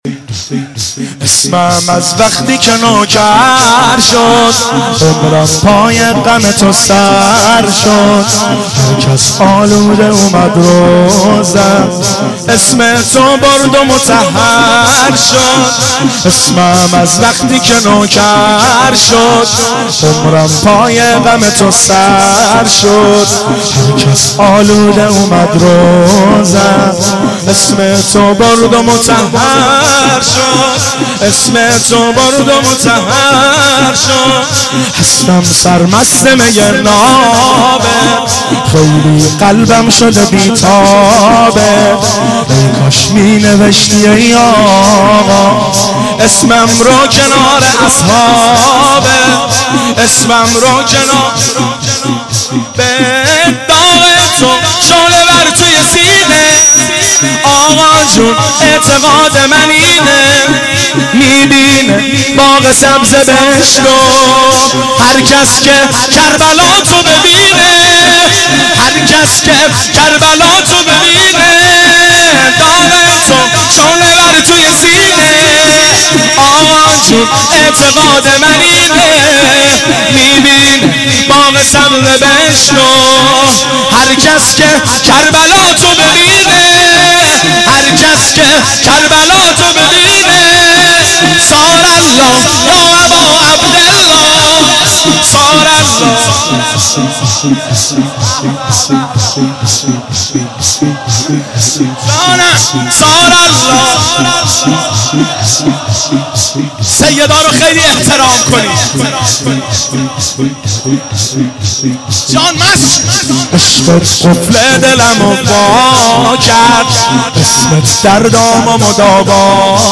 صوت مراسم شب نهم محرم (تاسوعا) ۱۴۳۷ هیئت غریب مدینه امیرکلا ذیلاً می‌آید: